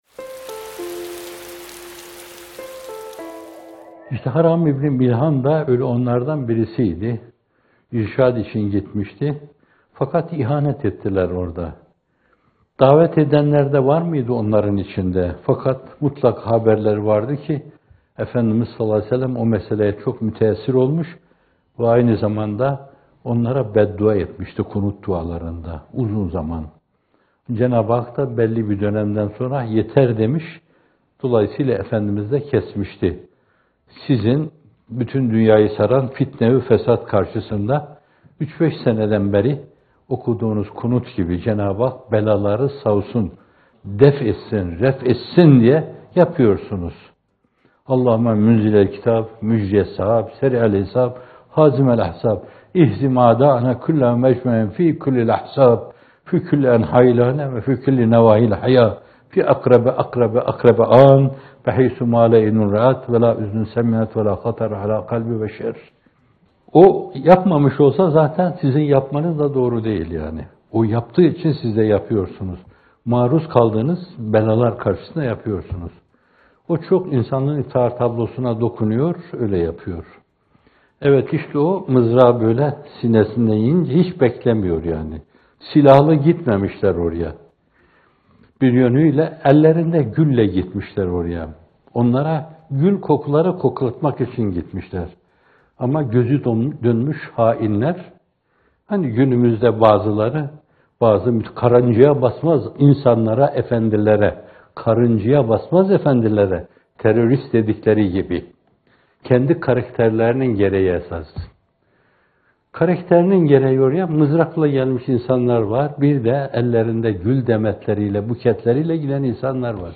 İkindi Yağmurları – Kazanmış Sayılırsınız - Fethullah Gülen Hocaefendi'nin Sohbetleri